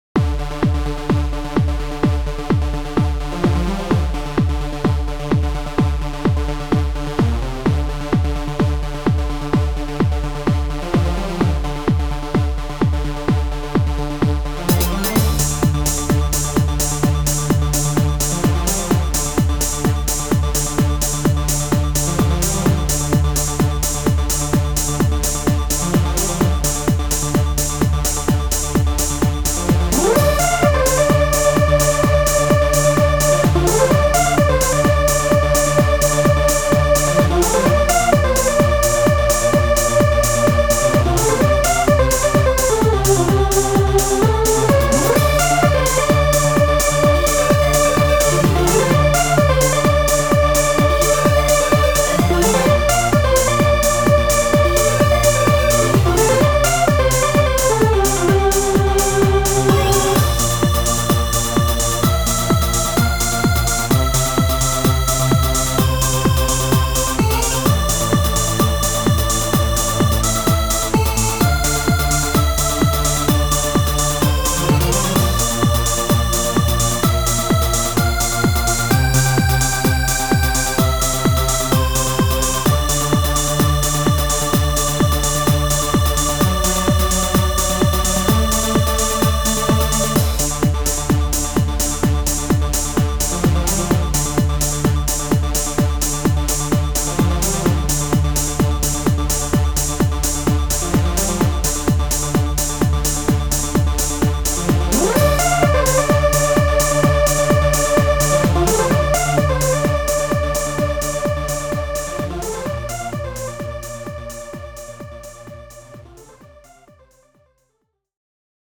サイバー系。私はシンセ系の楽器が好きなのかもしれい。